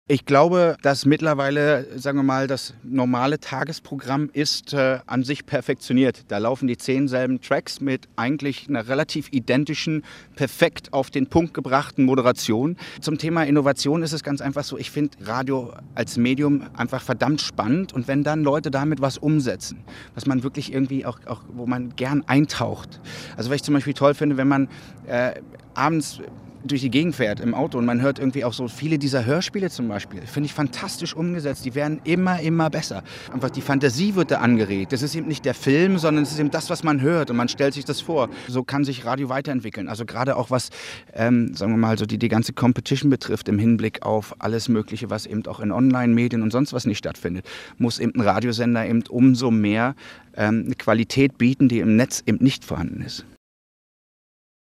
Trotz aller Formatierung hält Paul van Dyk Radio nach wie vor für ein spannendes Medium. (Interview